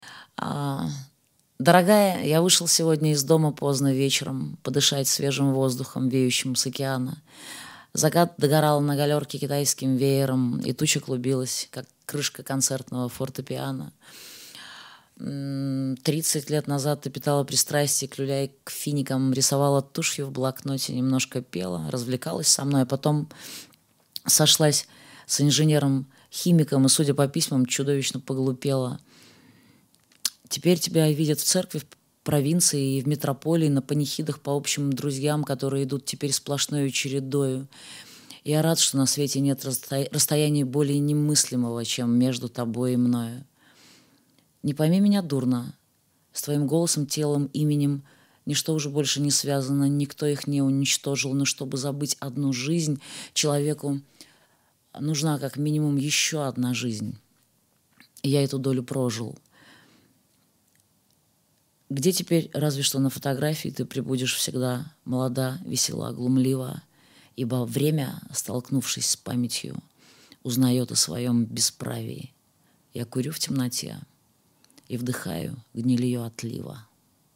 20. «Диана Арбенина читает стихи И. Бродского – Дорогая, я вышел сегодня из дому поздно вечером…» /
Arbenina-chitaet-stihi-I.-Brodskogo-Dorogaya-ya-vyshel-segodnya-iz-domu-pozdno-vecherom..-stih-club-ru.mp3